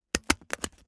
fo_stapler_02_hpx
Papers are stapled together. Paper, Staple